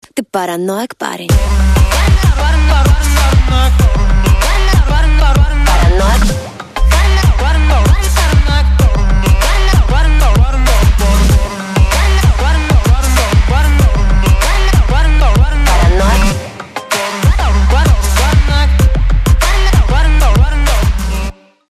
поп
женский вокал
dance
Electronic